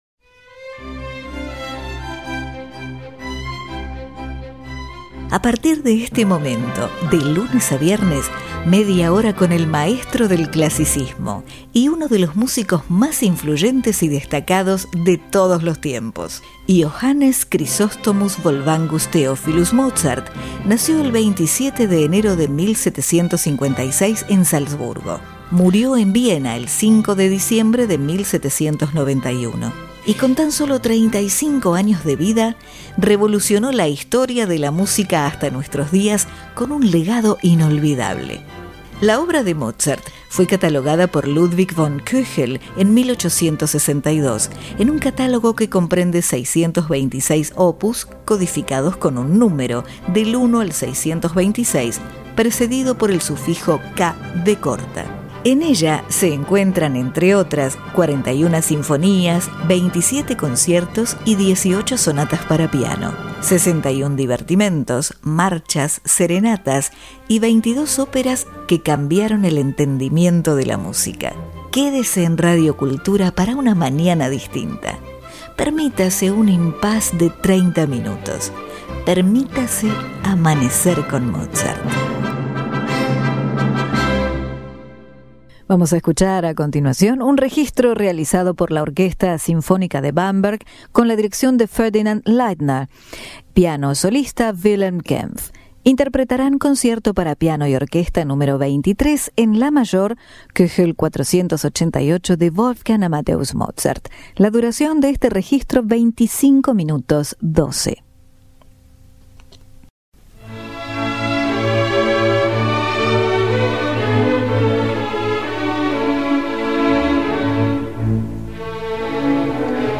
Orquesta Sinfónica De Bamberg Ferdinand Leitner